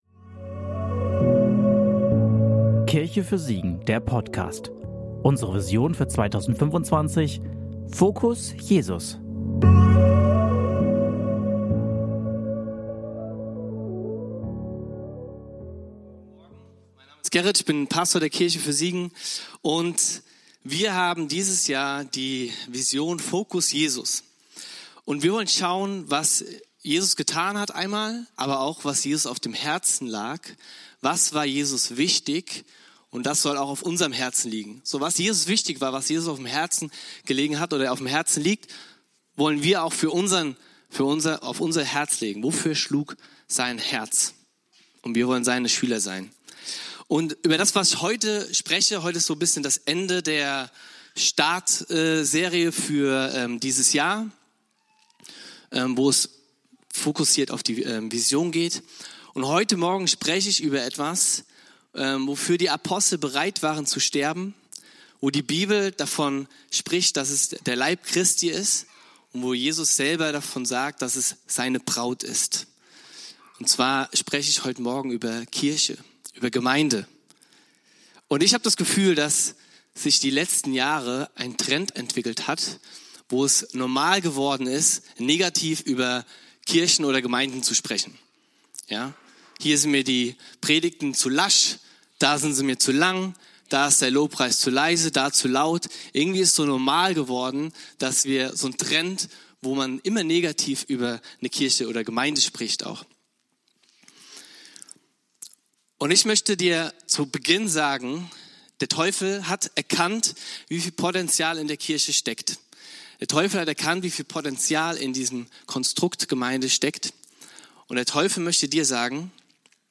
In dieser Predigt geht es darum, die Bedeutung der Kirche als einzigartigen Ort der Gemeinschaft zu erkennen, wo Menschen aus verschiedenen Lebensbereichen zusammenkommen, um Gott zu verehren.